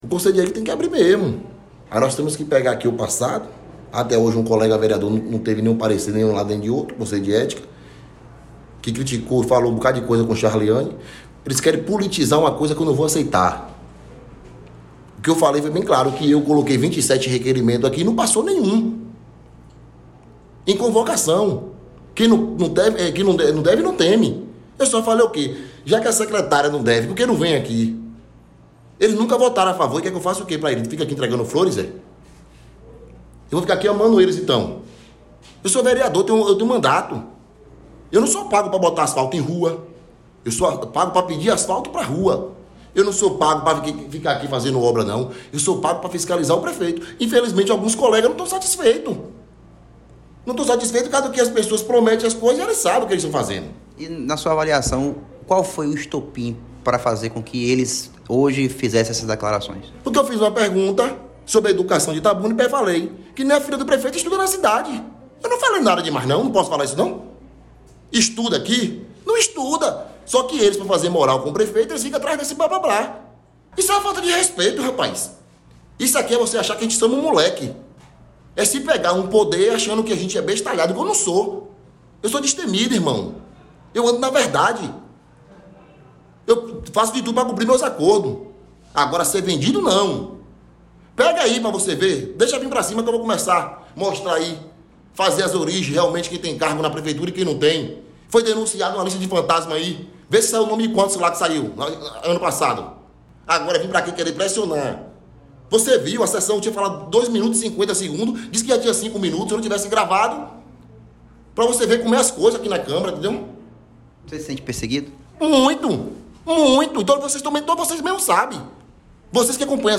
Danilo na mira: Vereador se diz perseguido e desabafa sobre clima de boicote na Câmara de Itabuna; ouça entrevista
fala-danilo.mp3